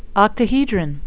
(oct-a-he-dron)